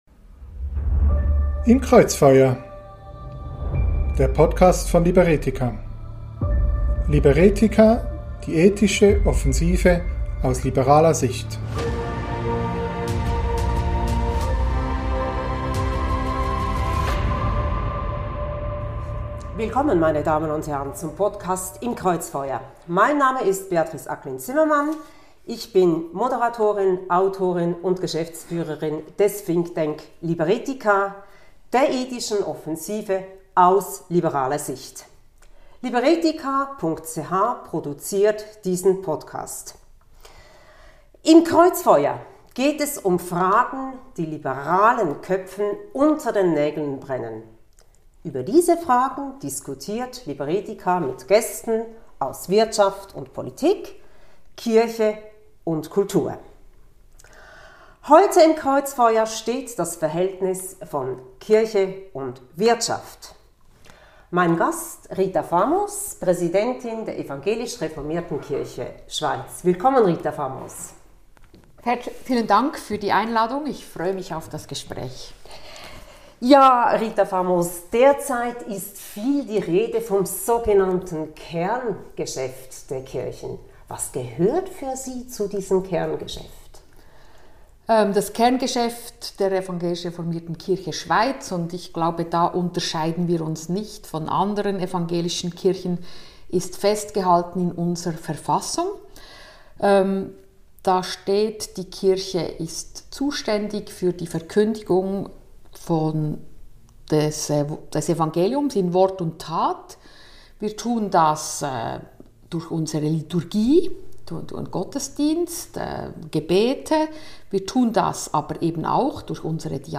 Wir sprechen mit Rita Famos, Präsidentin der Evangelisch-reformierten Kirche Schweiz, über die zunehmende Konfrontation von Kirche und Wirtschaft und fragen: Wie zerrüttet ist das Verhältnis zwischen Kirche und Wirtschaft? Weshalb findet die Wirtschaft, die den Menschen doch Arbeit und damit auch Würde verschafft, so wenig Wertschätzung in den Kirchen? Hat das angespannte Verhältnis auch damit zu tun, dass den Kirchen ihre ursprüngliche Streitkultur abhanden gekommen ist?